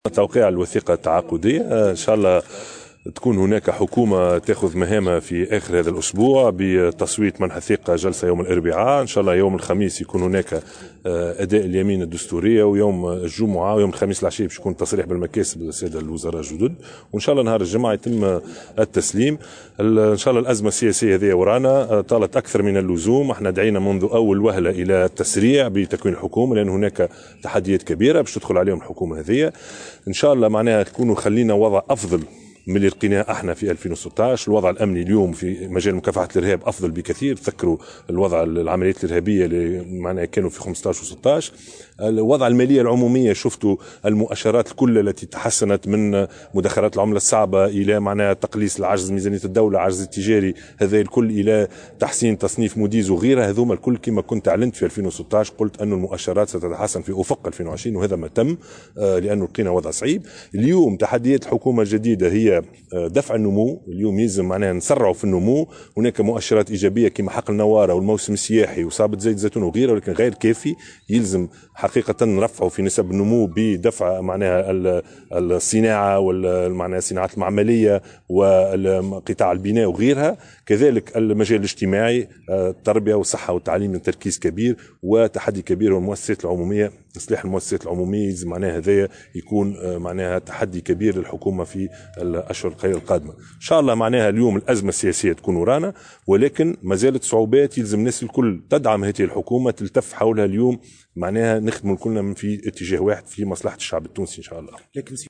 وصرّح لـ "الجوهرة أف أم" اثر موكب التوقيع على الوثيقة التعاقدية بين الأحزاب بدار الضيافة، أنه بعد منح الثقة للحكومة سيتم يوم الخميس اداء اليمن الدستورية قبل التصريح بالمكاسب بالنسبة لأعضاء الحكومة الجديدة على ان يتم في اليوم الموالي، الجمعة المقبل، تسليم مهام رئاسة الحكومة إلى إلياس الفخفاخ.